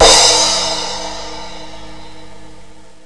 prato.wav